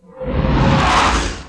Slam2-2.wav